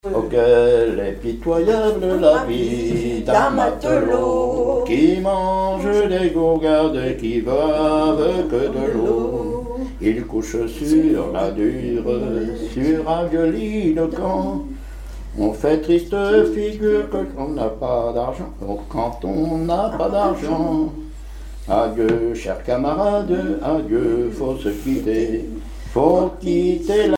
Chansons et commentaires
Pièce musicale inédite